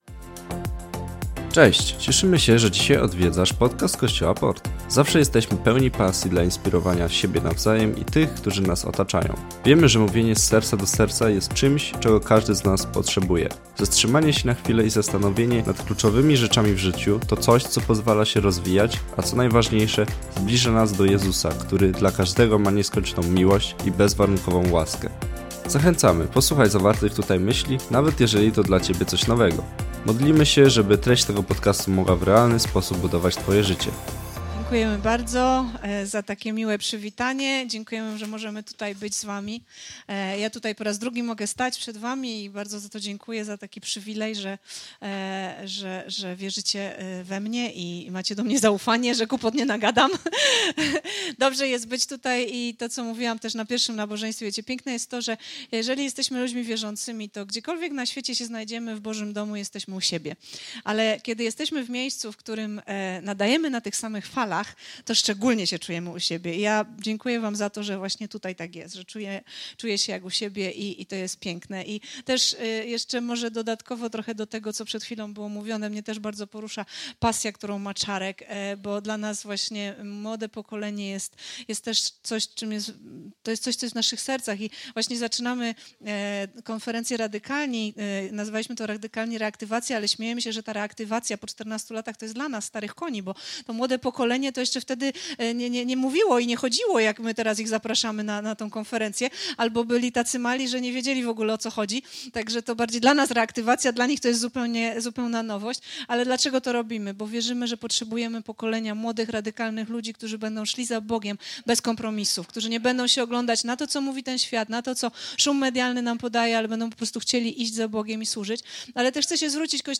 kazań